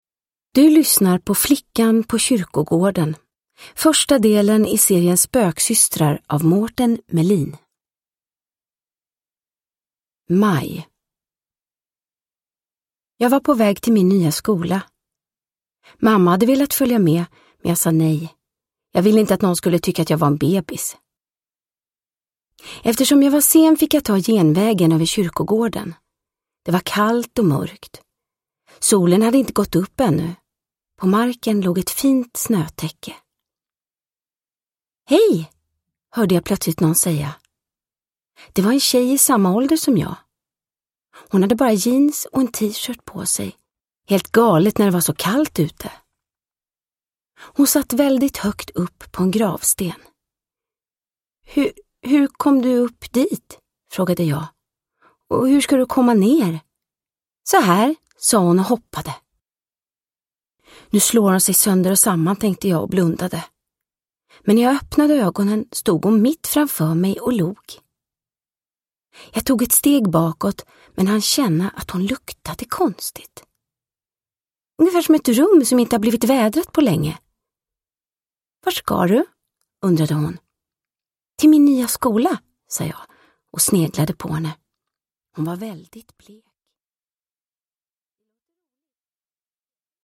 Flickan på kyrkogården – Ljudbok – Laddas ner
Uppläsare: Vanna Rosenberg